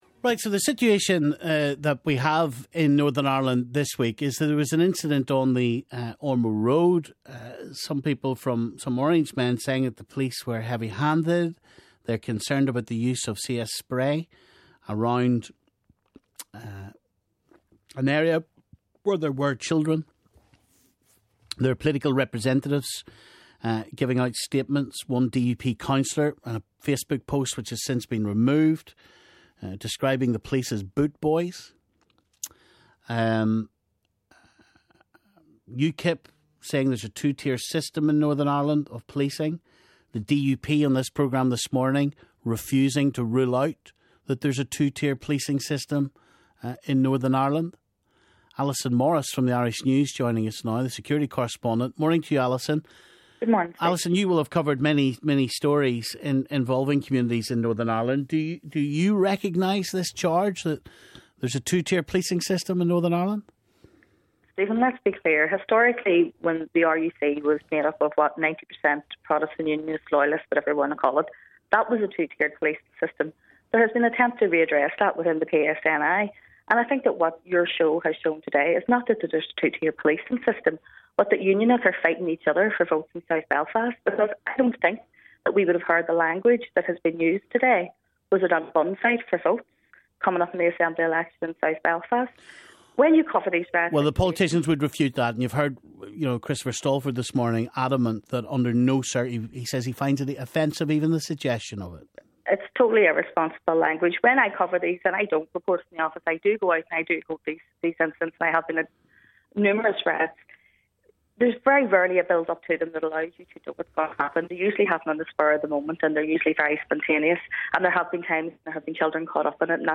Unionists challenged over reaction to CS spray incident - callers react